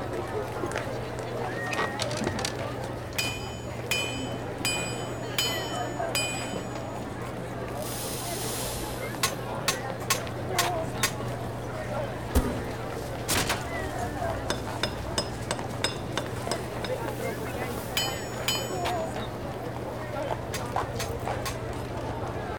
forge.ogg